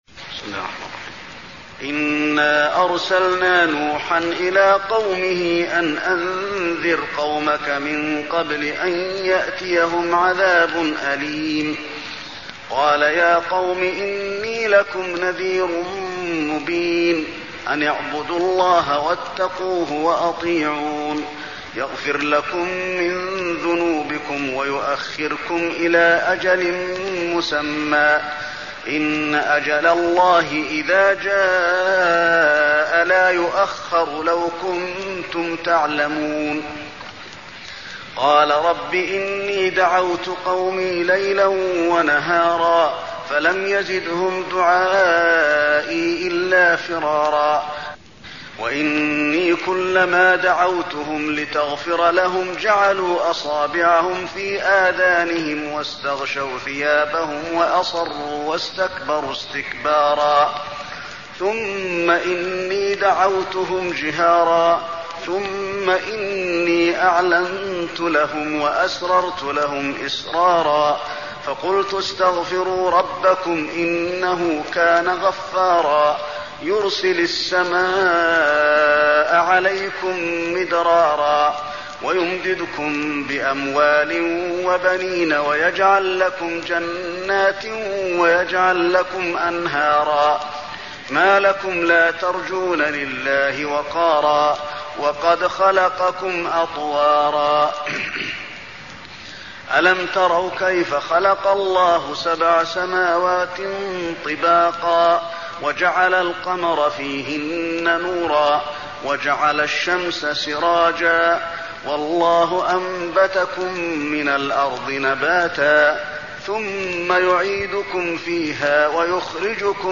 المكان: المسجد النبوي نوح